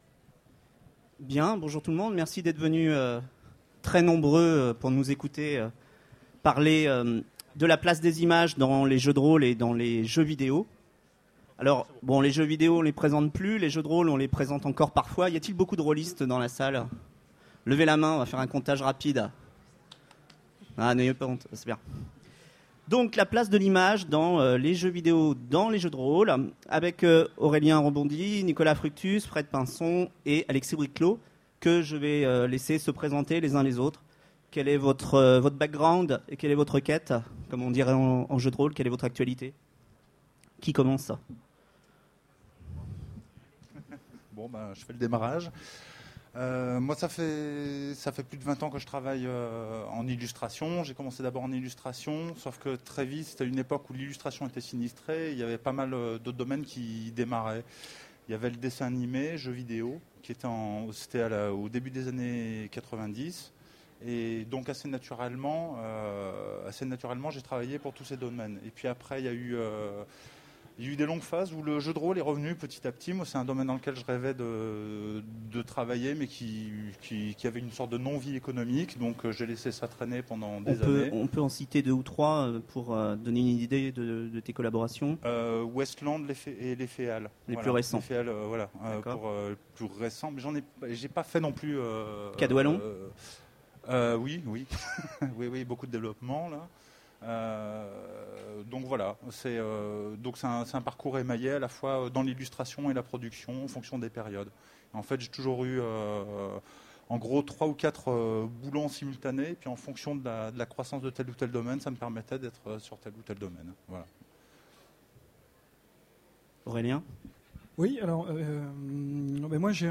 Utopiales 13 : Conférence Donner à voir, donner à penser, l'image au service du jeu vidéo et du jeu de rôles
Conférence